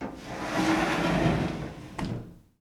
household
Glass Shower Door Close